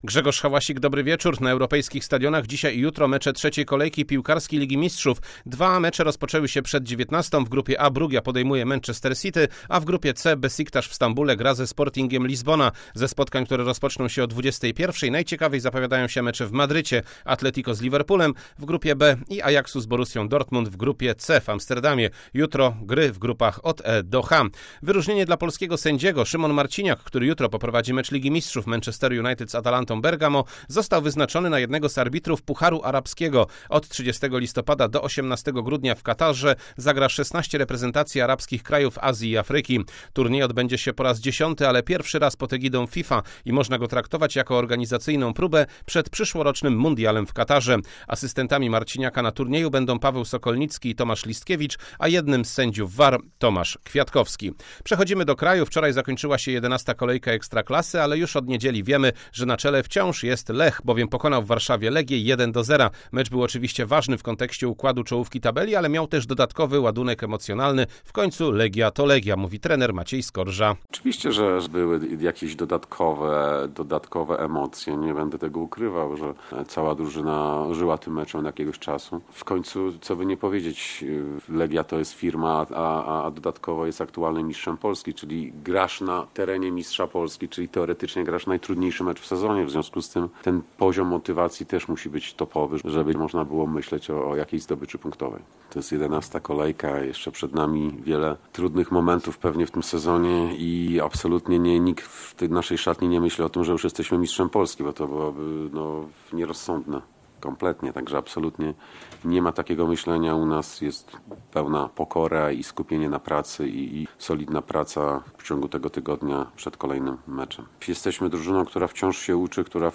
19.10.2021 SERWIS SPORTOWY GODZ. 19:05